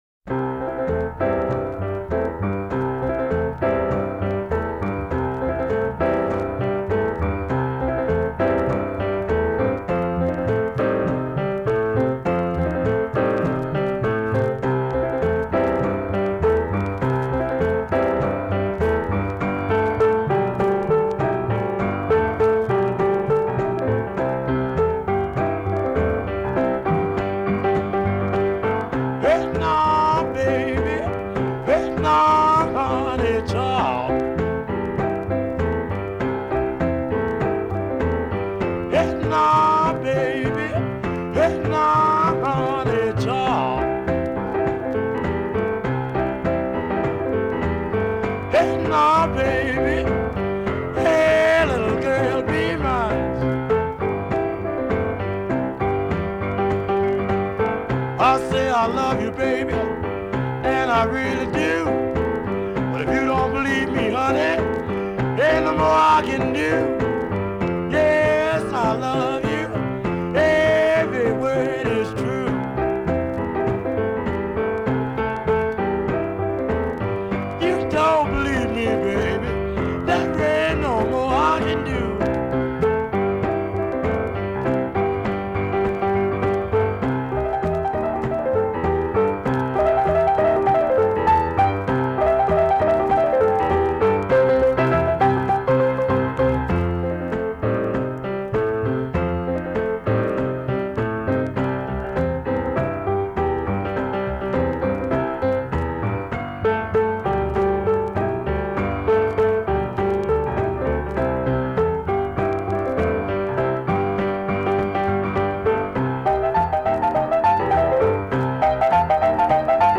This is classic New Orleans sound.
was a New Orleans rhythm & blues singer and pianist.